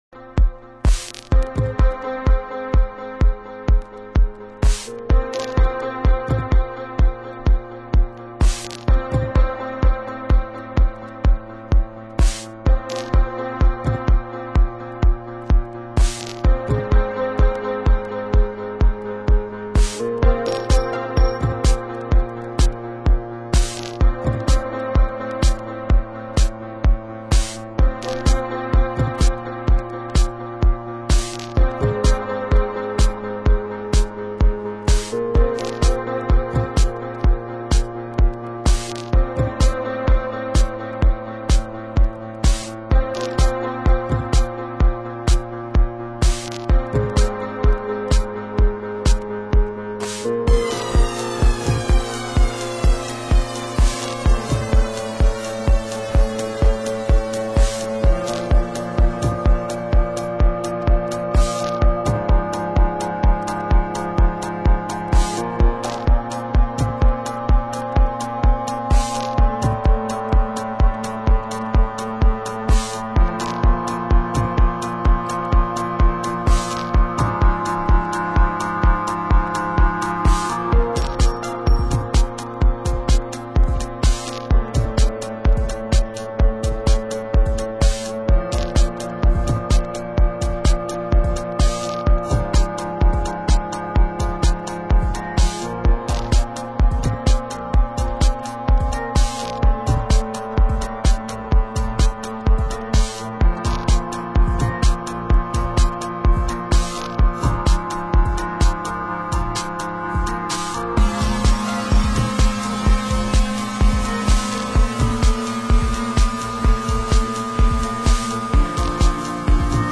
Genre: Minimal